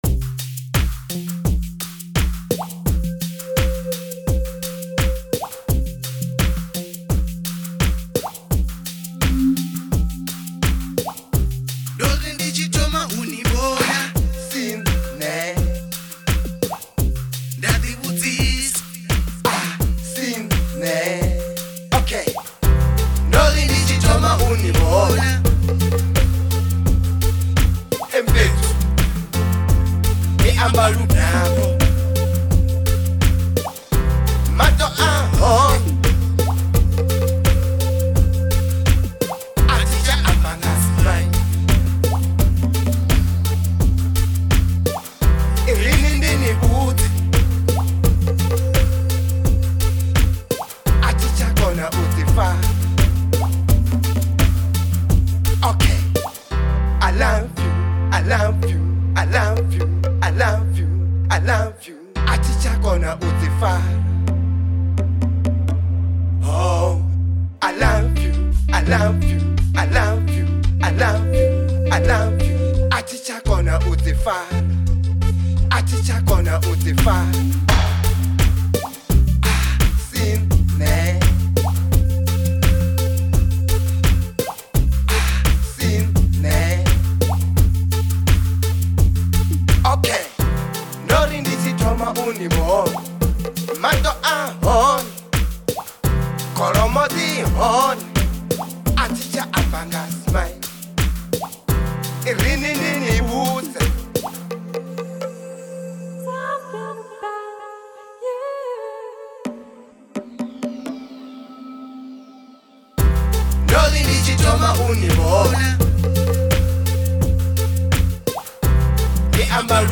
03:12 Genre : Venrap Size